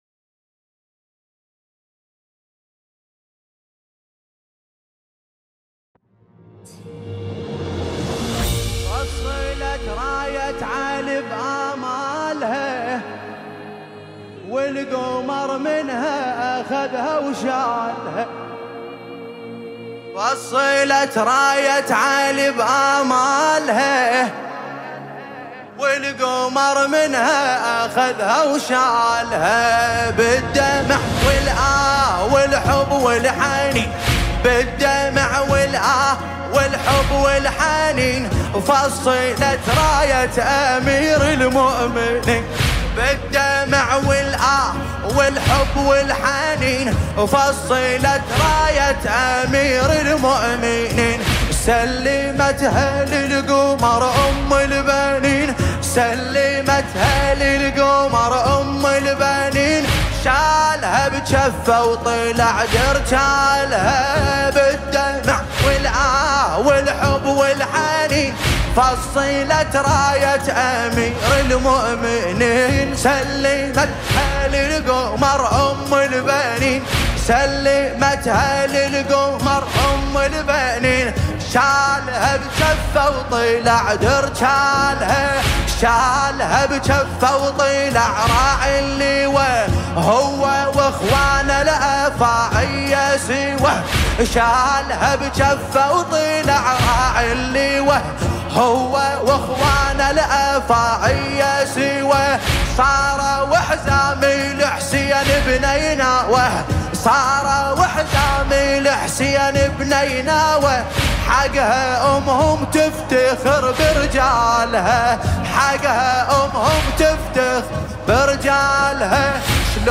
شهر محرم الحرام 1447 هـ
الرادود